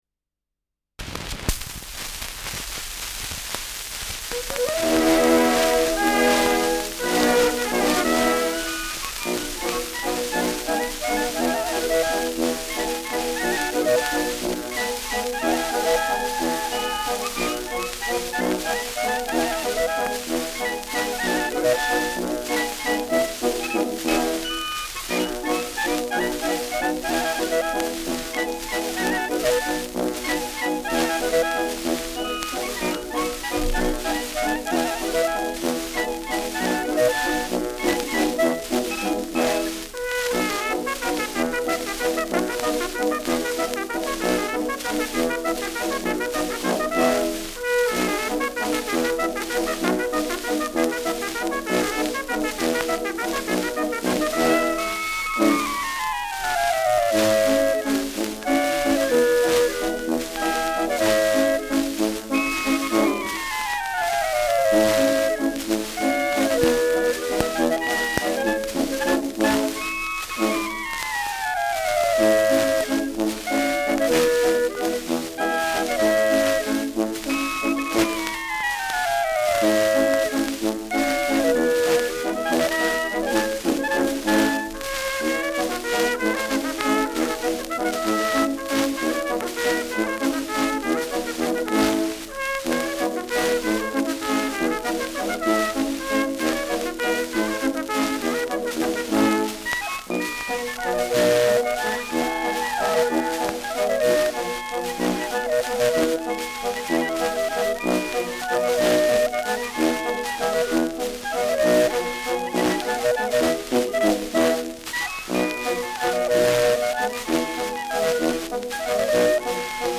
Schellackplatte
Starkes Grundrauschen : Abgespielt : Vereinzelt leichtes Knacken : Nadelgeräusch
Kapelle Schmidt, Nürnberg (Interpretation)